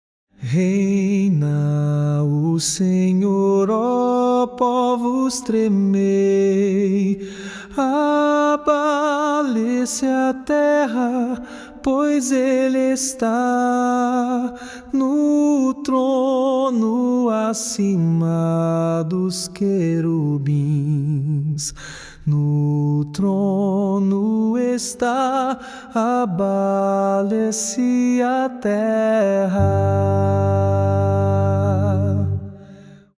Modo: jônio
Harmonização: Claude Goudimel, 1564
salmo_99A_cantado.mp3